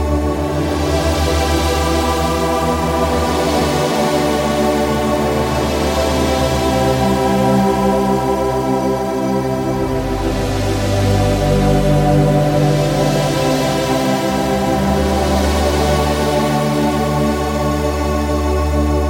E D C G C D (对不起，101 BPM)
Tag: 101 bpm Chill Out Loops Pad Loops 3.21 MB wav Key : Unknown